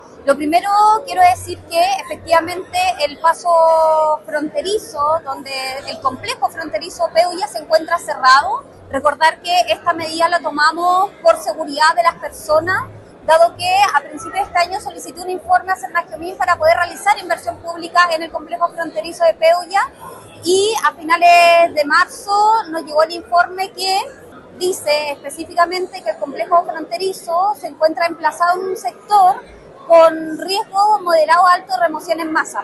La Delegada Presidencial de la Región de Los Lagos, Giovanna Moreira, explicó que el cierre es una medida de precaución para la seguridad de las personas.